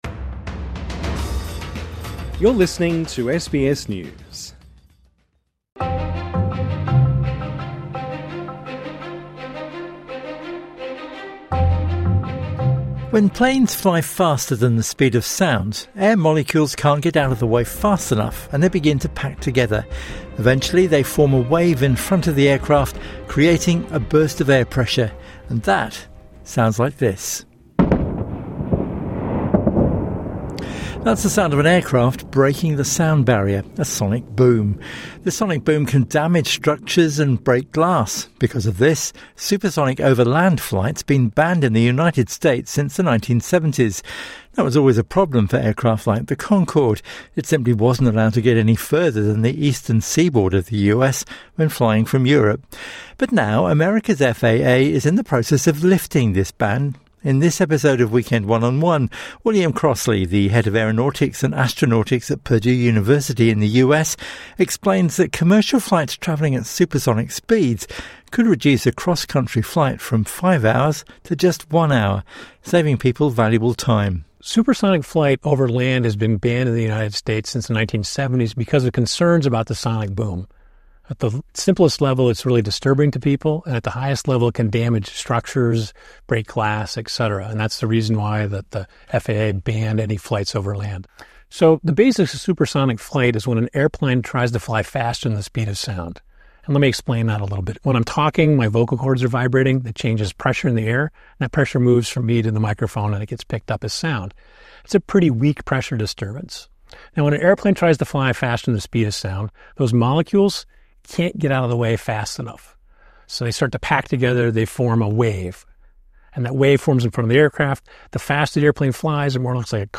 INTERVIEW: 22 years after Concorde's last flight - could supersonic travel be back in the air